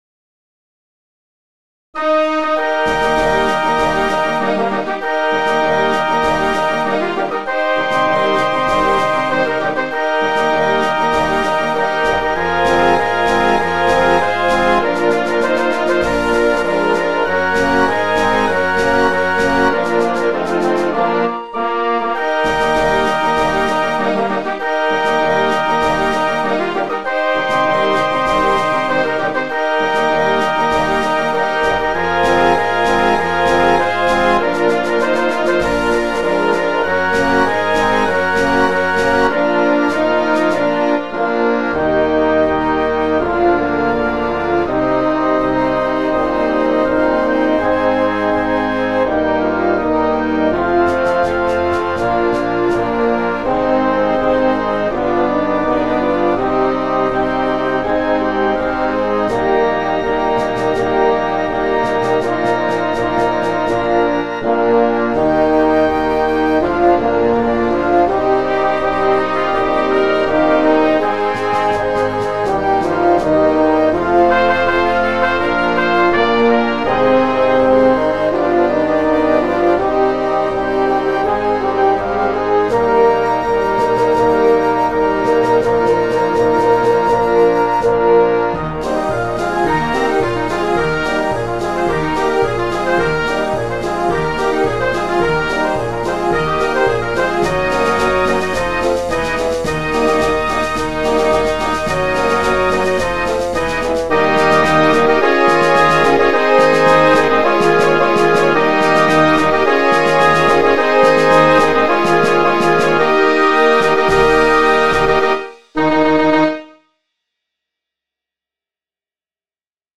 Besetzung Sinfonisches Blasorchester